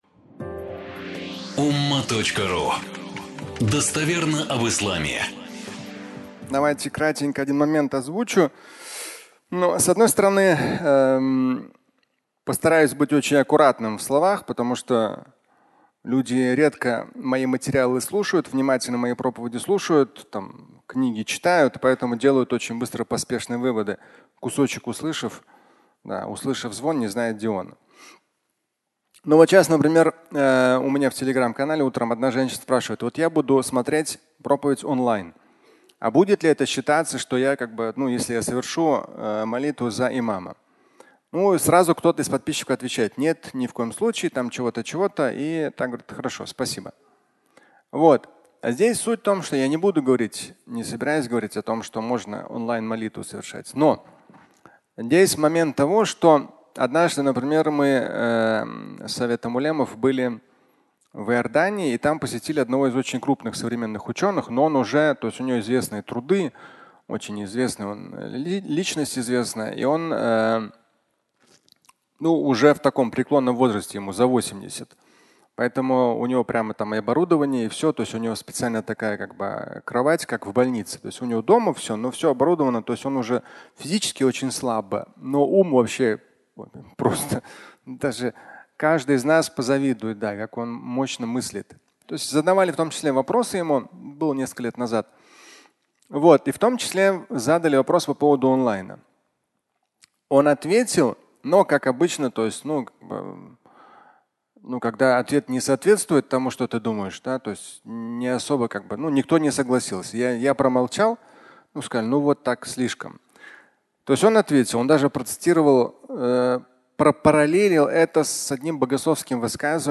Молитва онлайн (аудиолекция)